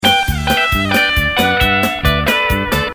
Meine aktuell verlinke Version ist komplett in reinem Dur gespielt.
pekingsnip.mp3